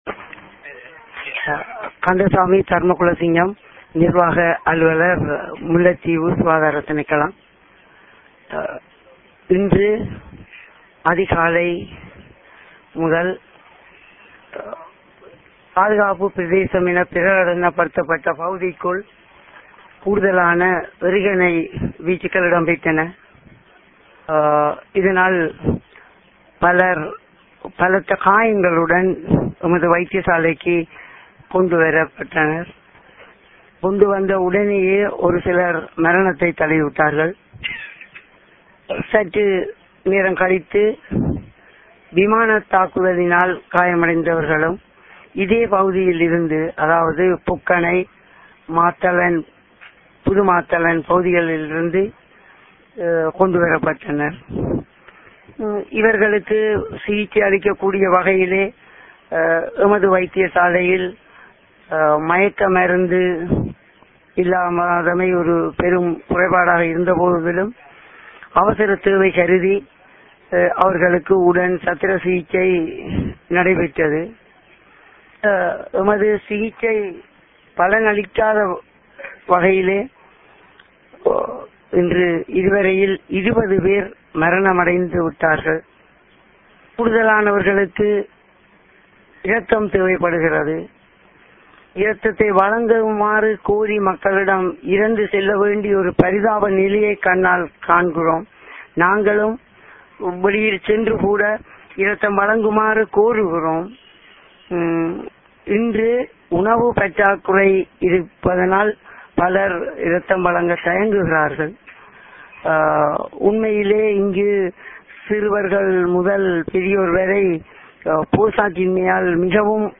Voice: Interview